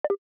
sound_back.wav